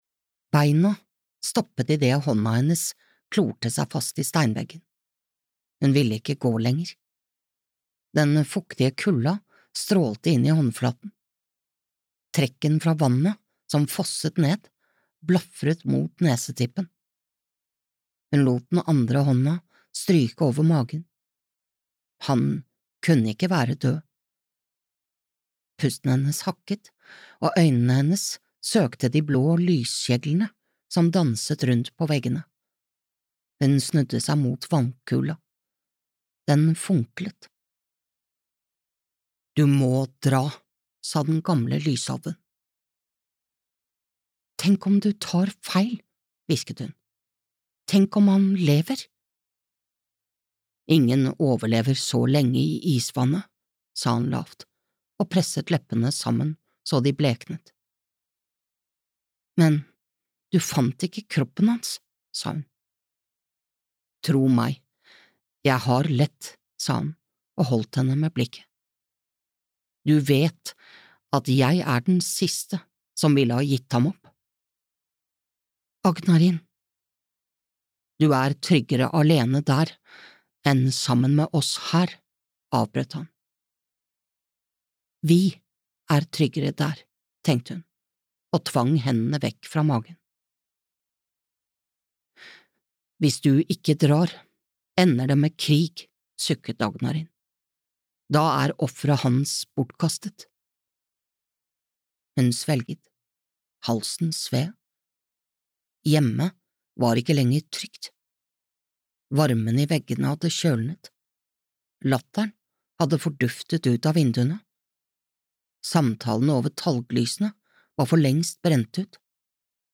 Glimmersvippen (lydbok) av Birgitte Wærstad